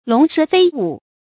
龍蛇飛舞 注音： ㄌㄨㄙˊ ㄕㄜˊ ㄈㄟ ㄨˇ 讀音讀法： 意思解釋： 見「龍蛇飛動」。